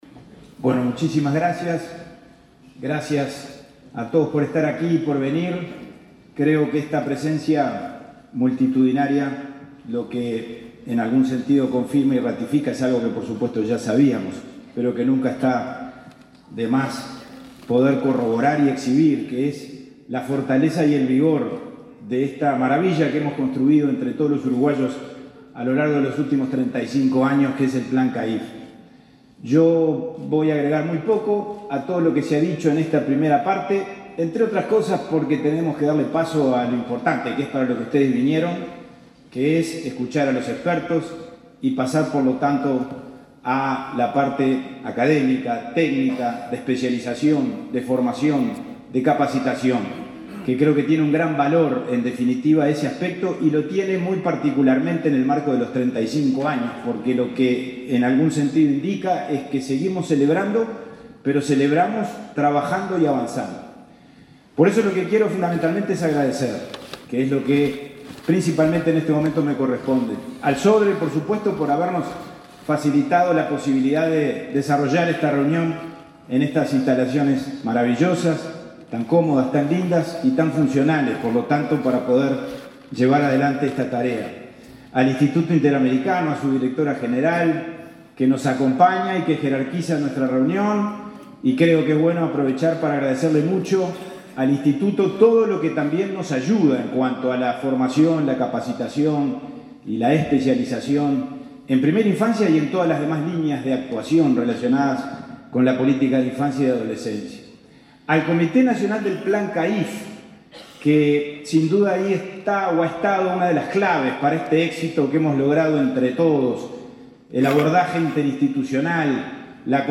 Palabras del presidente del INAU, Pablo Abdala
Palabras del presidente del INAU, Pablo Abdala 14/08/2023 Compartir Facebook X Copiar enlace WhatsApp LinkedIn Este lunes 14 en Montevideo, el presidente de Instituto del Niño y el Adolescente del Uruguay (INAU), Pablo Abdala, participó en un seminario por los 35 años del plan CAIF.